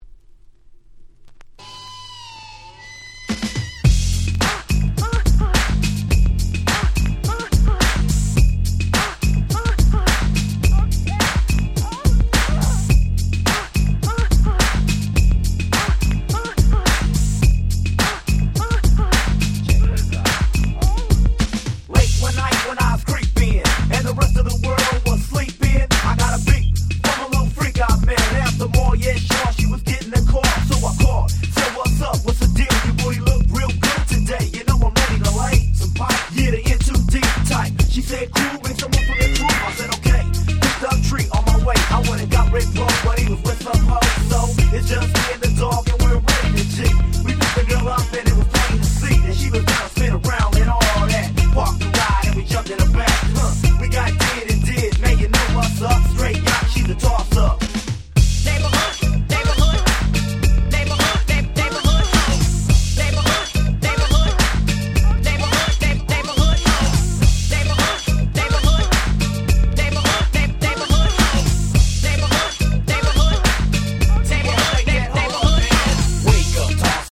92' Smash Hit Hip Hop / Gangsta Rap !!
時代柄若干のNew School感も残しつつブリブリのBeatが気持ち良いフロアヒットです！